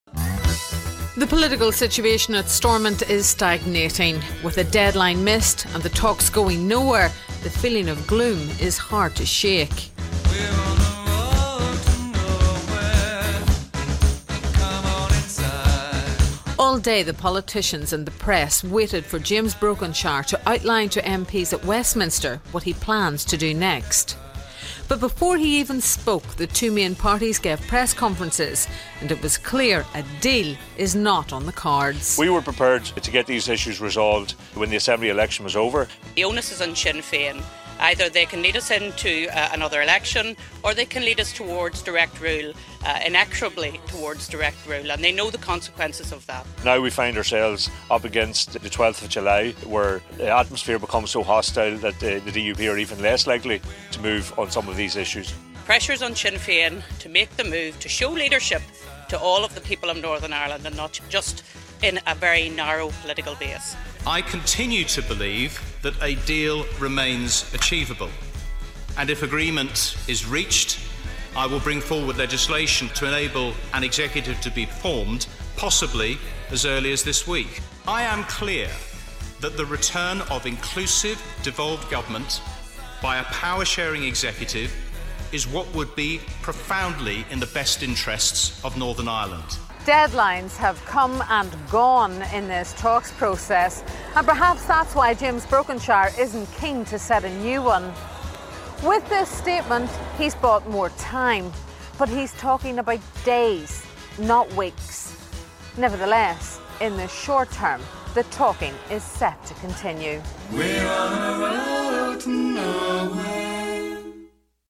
A musical montage of the last 24 hours in politics...